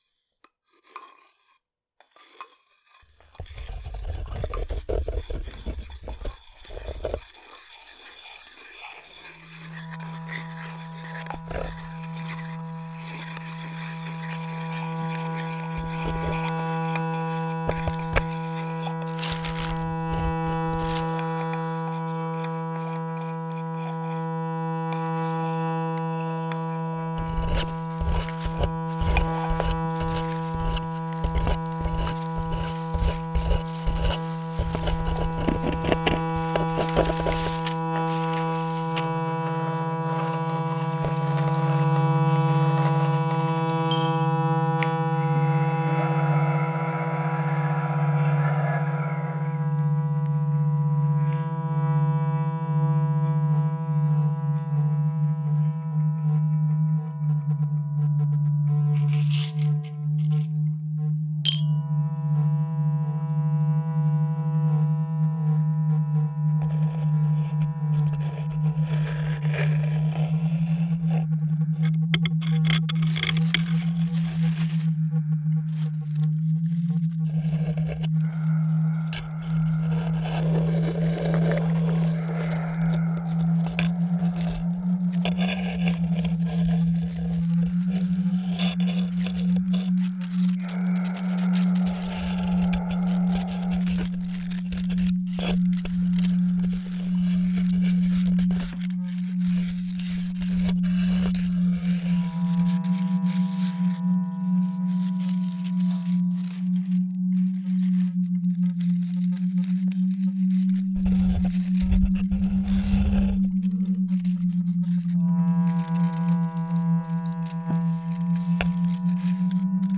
music accompanying the exhibition